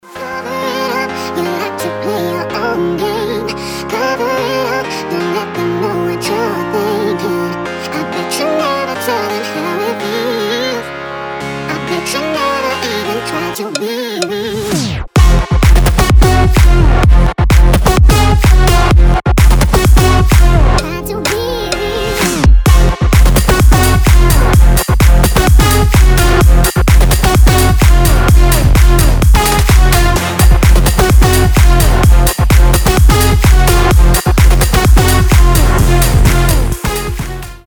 • Качество: 320, Stereo
громкие
EDM
future house
Big Room
забавный голос
electro house
Энергичная клубная музыка с забавным голосом